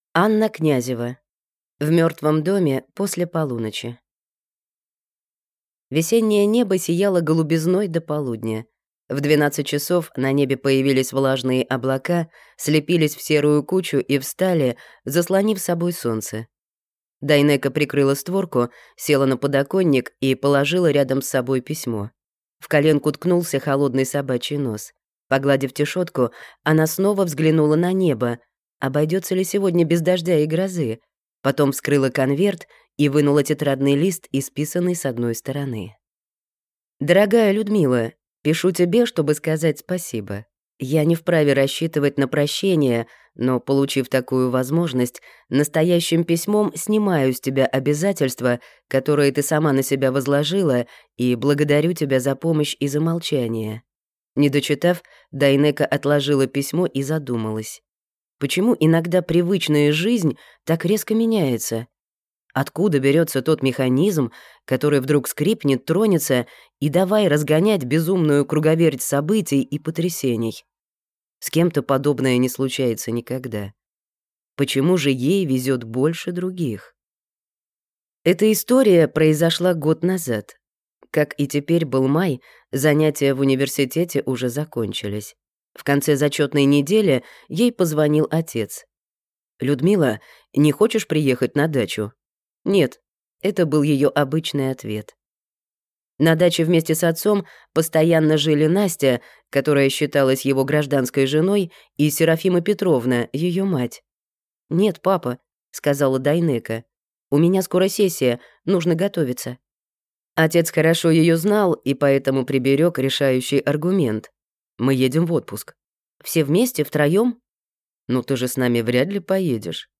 Аудиокнига В мертвом доме после полуночи (рассказ) | Библиотека аудиокниг